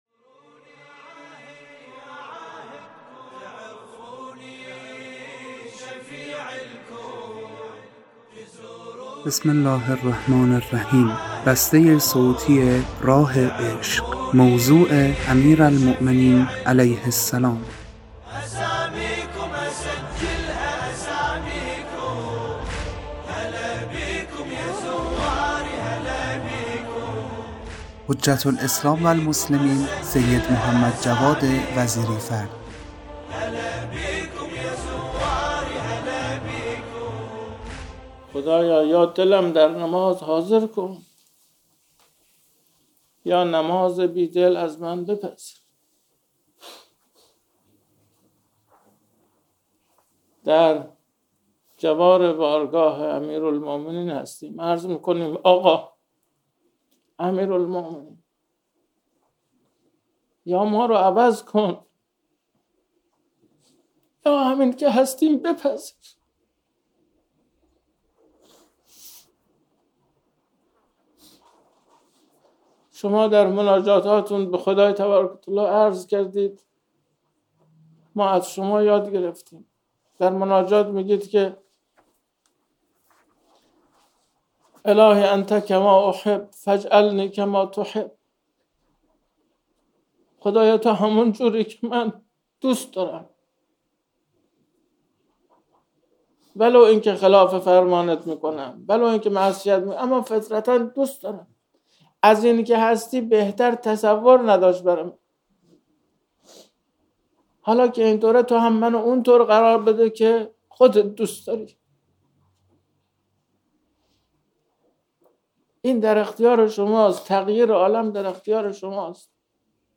در سفرهای زیارتی کربلا بیان فرمودند.